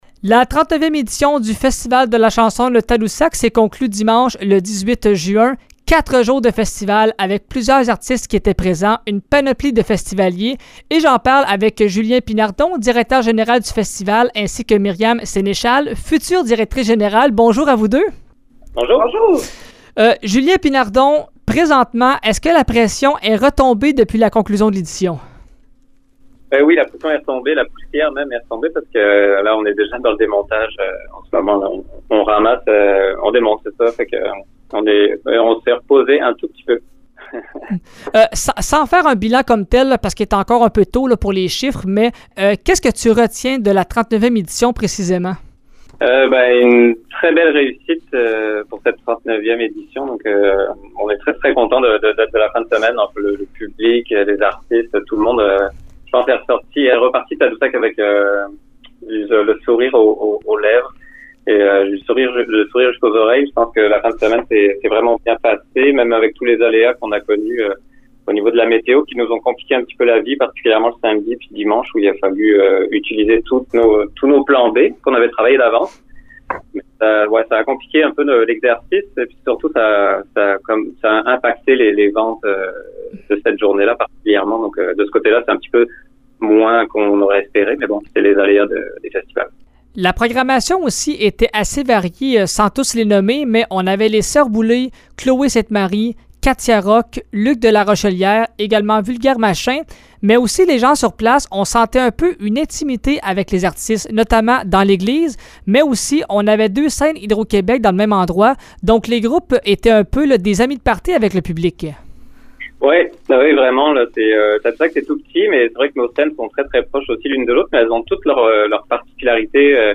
Voici l’entrevue